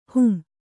♪ hum